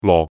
Yue-lo4.mp3